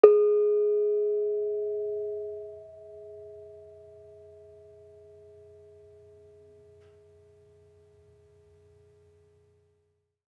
Bonang-G#3-f.wav